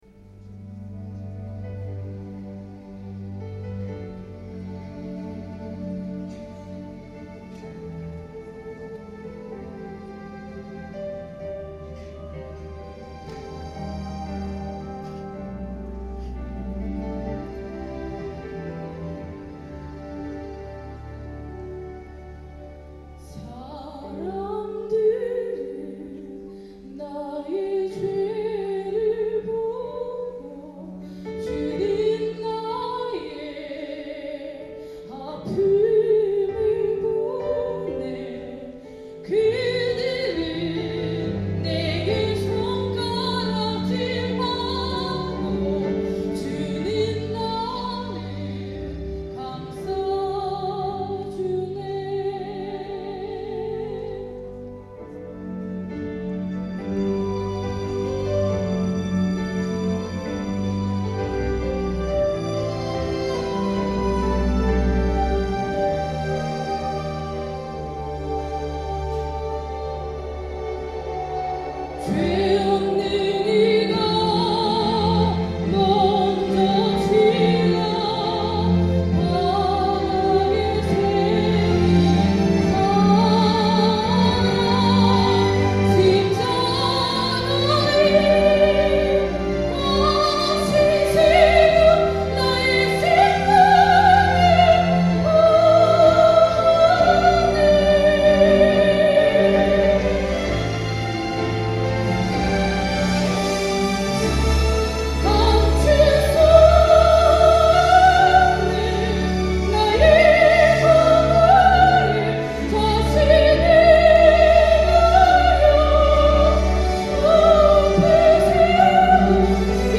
These songs are best heard on headphones to hear the direction of chatter, laughter and so on.
What surprised me is the varied styles of singing in this group.
Some distortion at the loud bits.
Don’t know what the song was about, but despite the big soaring notes, I felt quite sad and depleted at the end of the song.